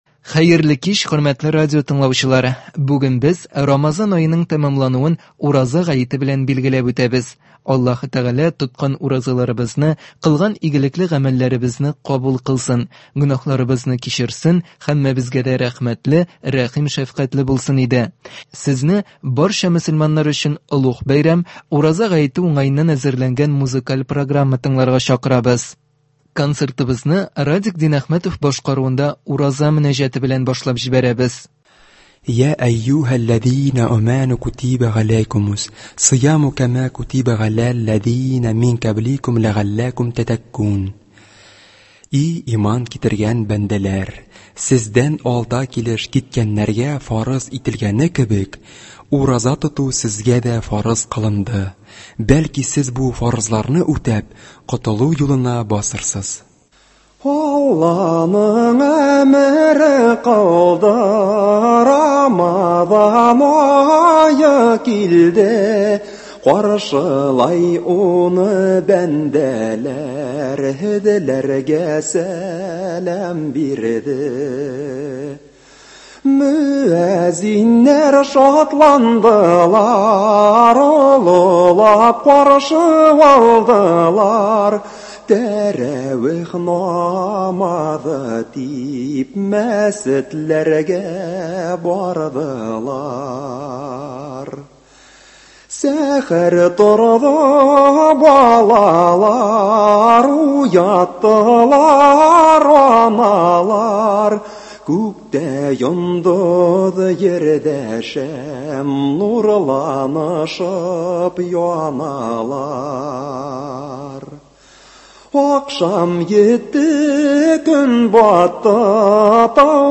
Бәйрәм концерты.